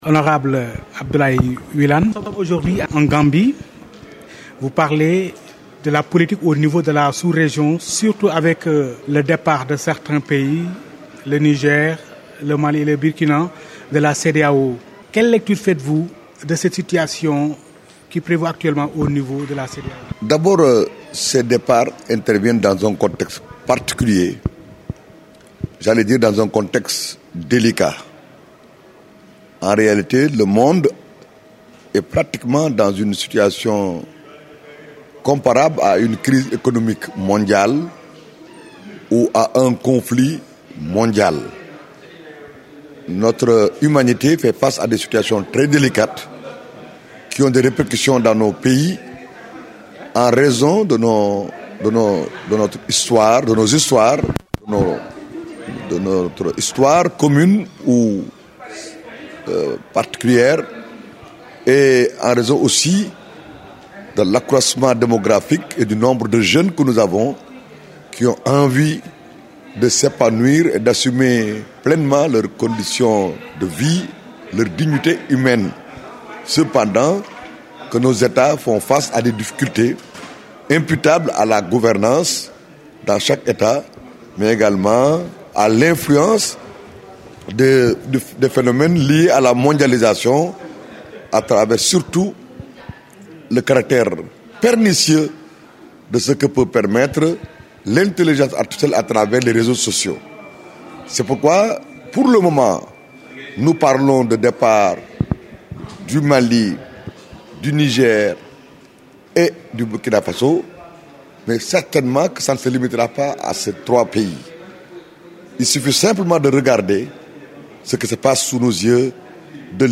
Entretien-exclusif-avec-lhonorable-Abdoulaye-Wilane-depuis-Banjul-fr.mp3